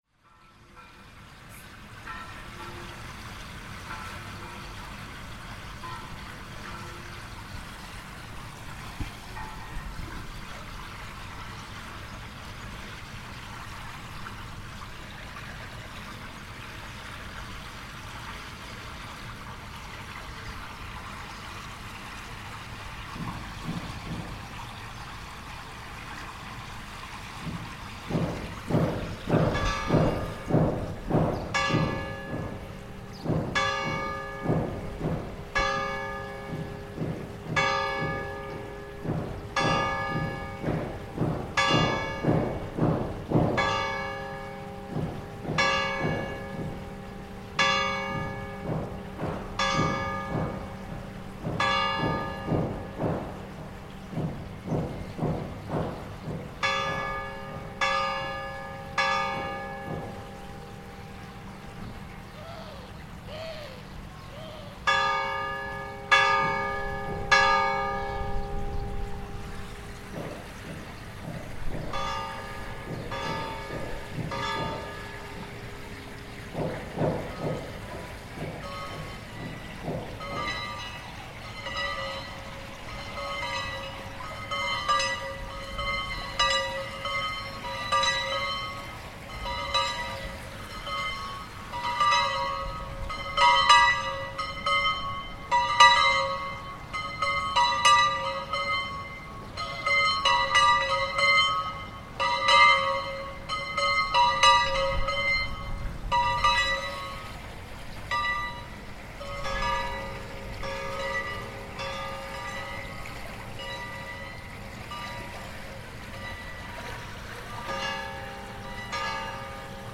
Midday bells in Seville
Bells ring out middday across the old town in Seville, Spain.